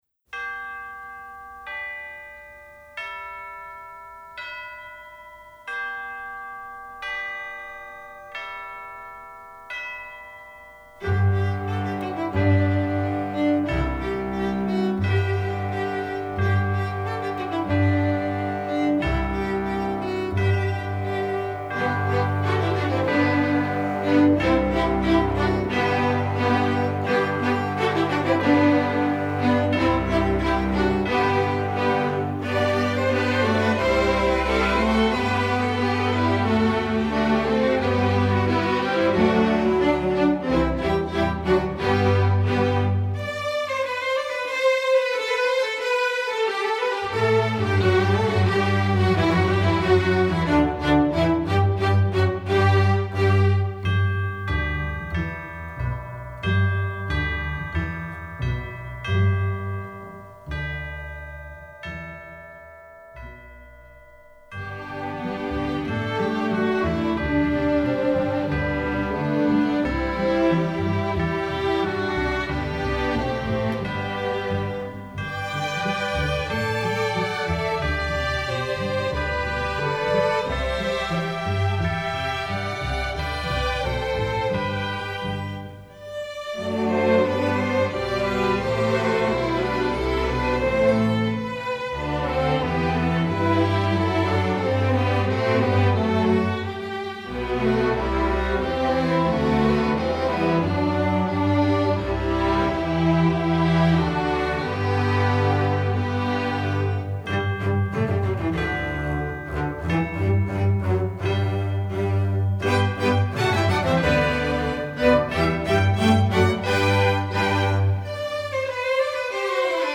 Instrumentation: string orchestra
Piano accompaniment part:
1st percussion part: